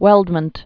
(wĕldmənt)